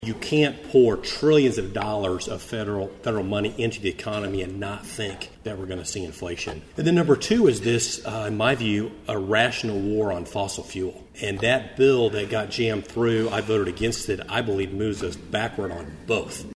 1st District Congressman Tracey Mann was the featured guest at the Manhattan Area Chamber of Commerce Military Relations Committee Luncheon, held Wednesday at the Manhattan Conference Center.